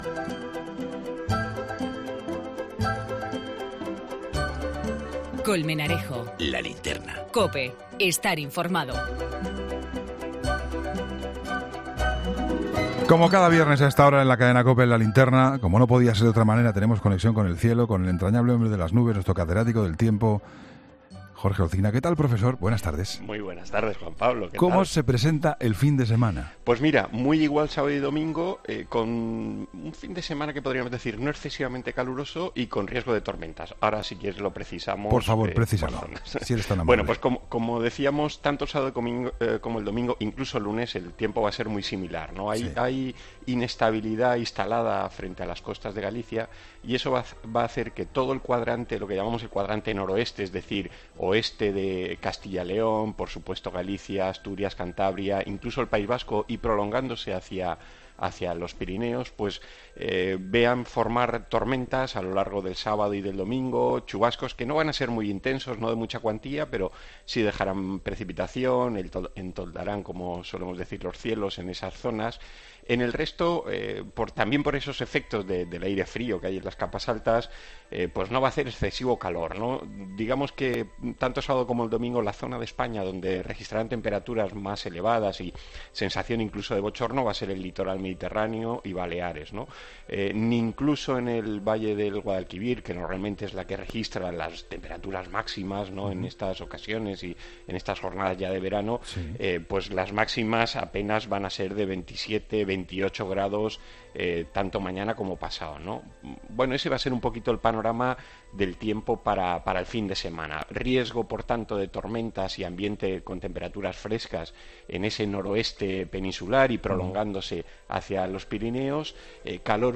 habla en ‘La Linterna’ sobre el tiburón blanco avistado en aguas de Baleares